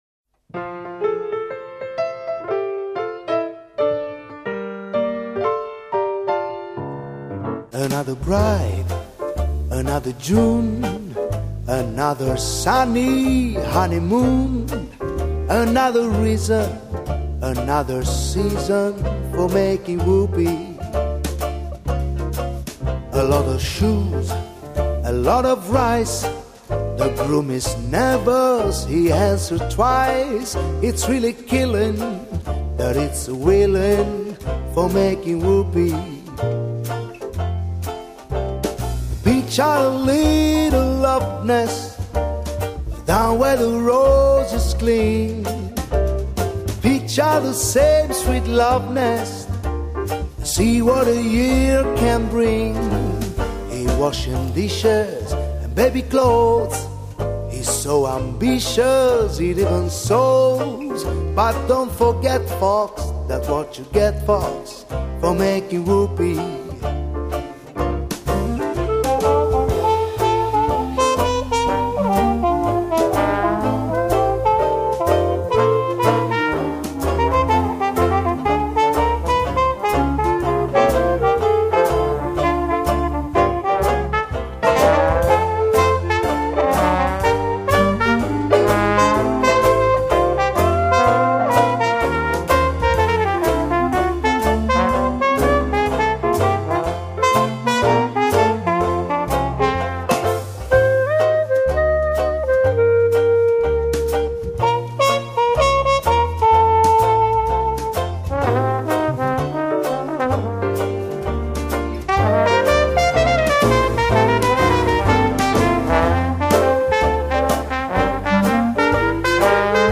类别： 爵士
主奏乐器：钢琴
一群热爱爵士的乐手以不同的爵士乐风，烘托城市的美妙炫丽，用另一种角度观看城市风情~~~
★ 挑逗感性再次翻释演出★令人感动无压的爵士空间★符合应景的温馨感动小品。